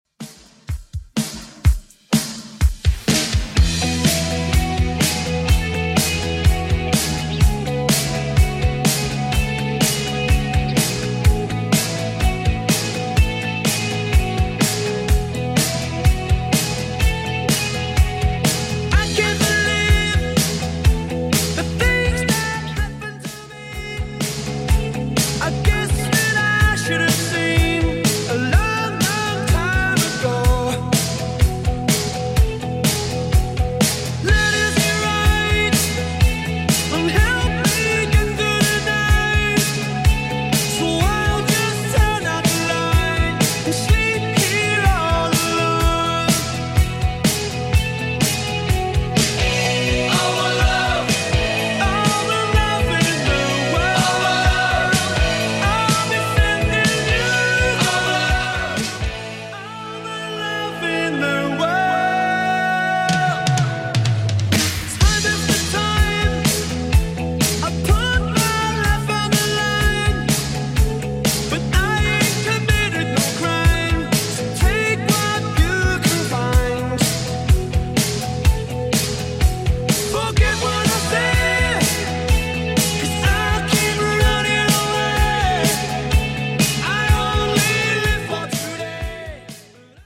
80s Rock Redrum) 125bpm CLEAN